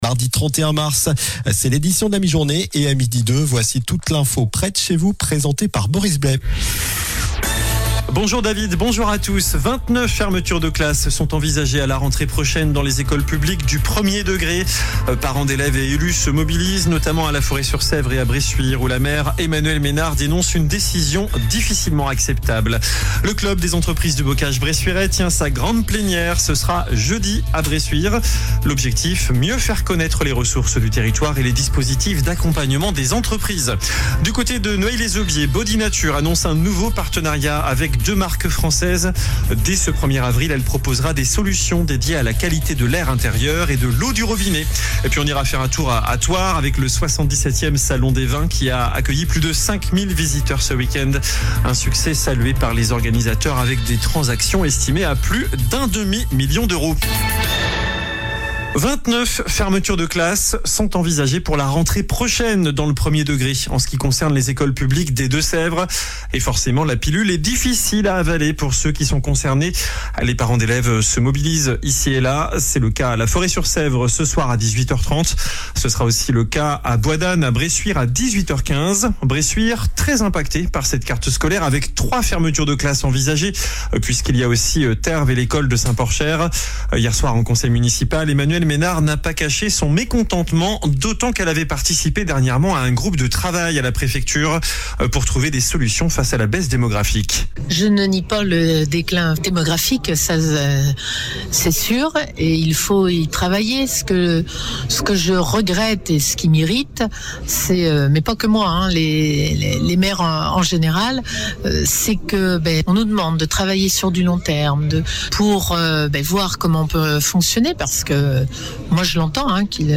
Journal du mardi 31 mars (midi)